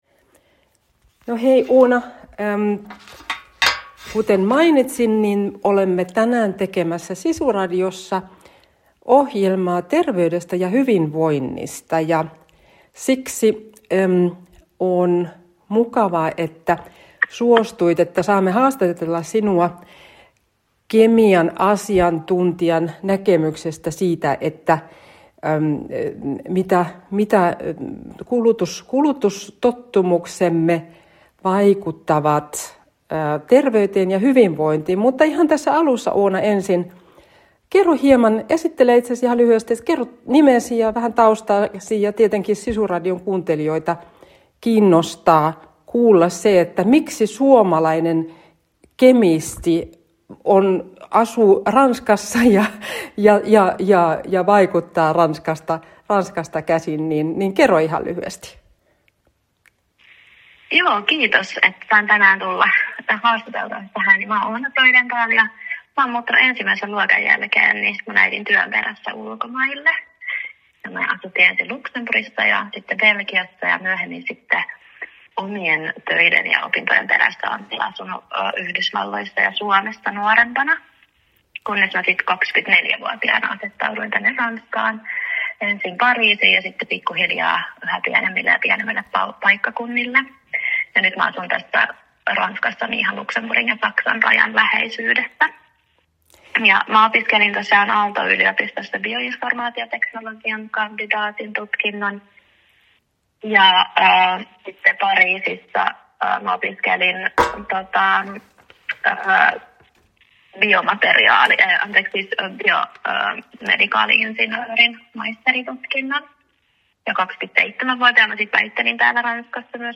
Haastattelussa käsiteltiin terveyttä ja hyvinvointia koskevia asioita.